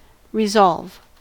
resolve: Wikimedia Commons US English Pronunciations
En-us-resolve.WAV